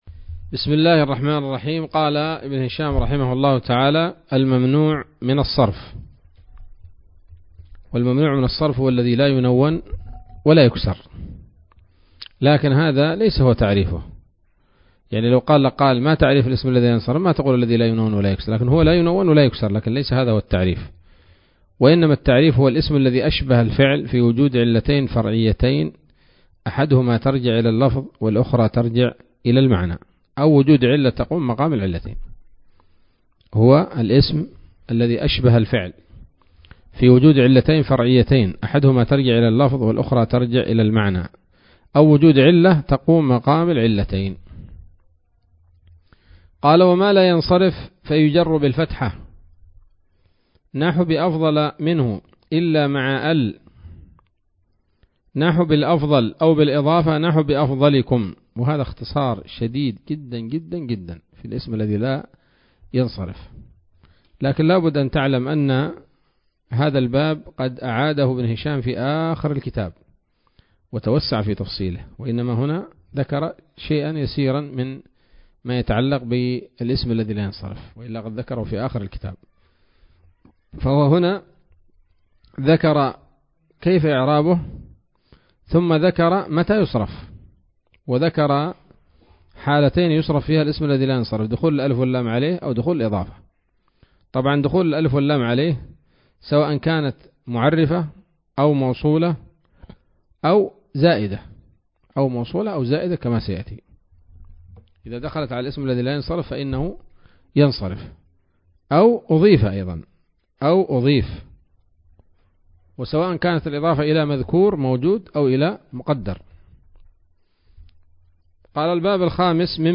الدرس الحادي والعشرون من شرح قطر الندى وبل الصدى [1444هـ]